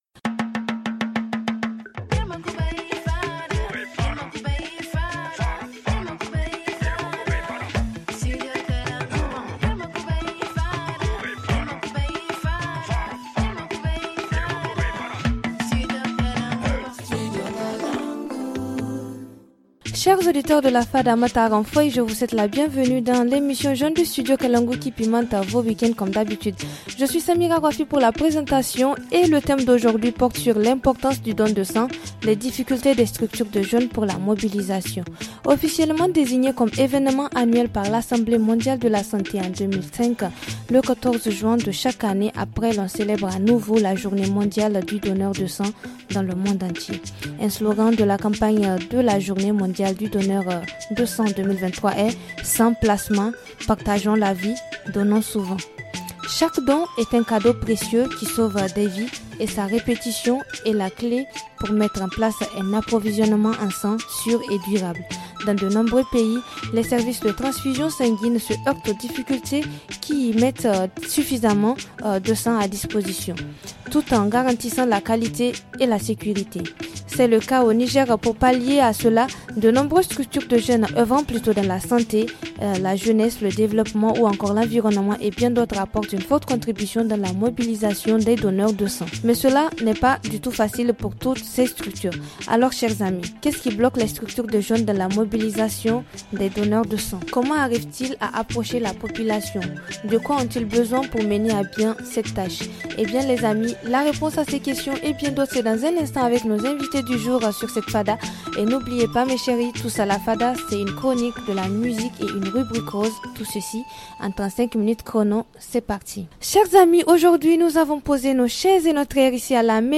Pour en parler nous avons comme invités :